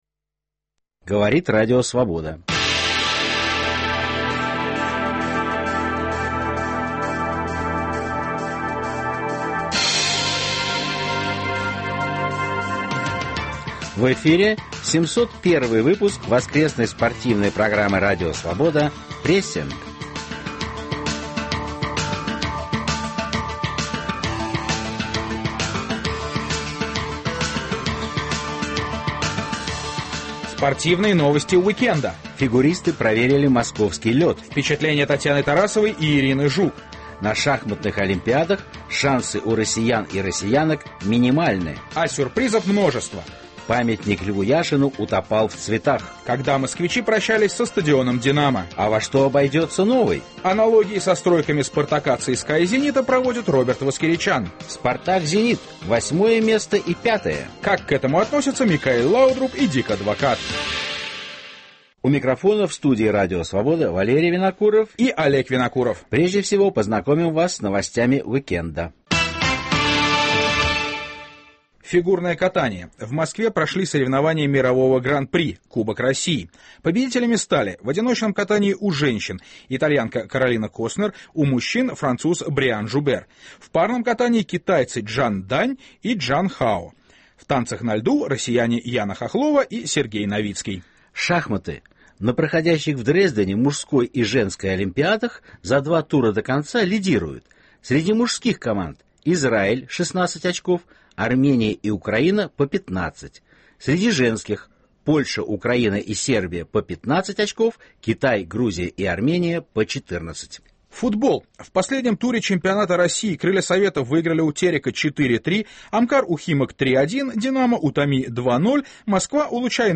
В спортивной программе "Прессинг" - не только свежая информация, анализ и размышления, но и голоса спортсменов и тренеров всего мира с откровениями о жизни, о партнерах и соперниках. Речь не только о самом спорте, ибо он неотделим от социальных, экономических, нравственных и национальных проблем.